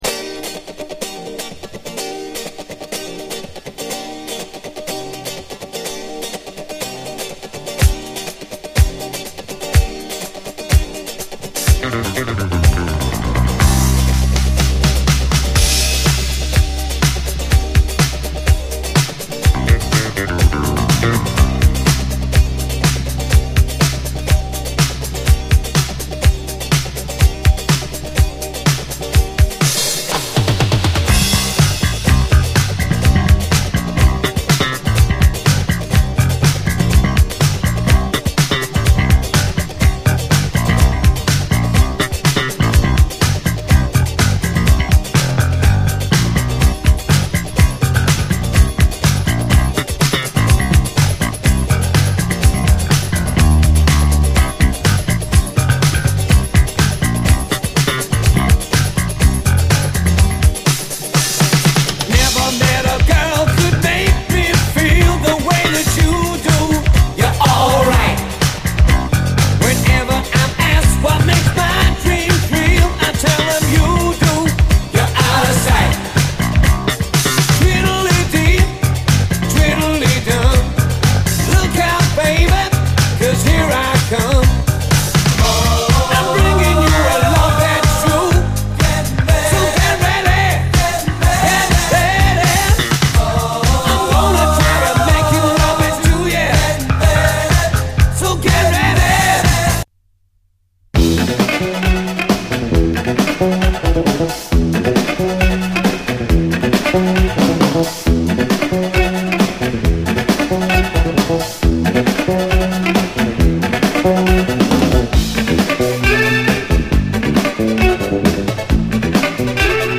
チャーミングな女性ヴォーカル入りのスウィート・ラテン・ソウル
ヴォブラフォンが揺れるラテン・ジャズ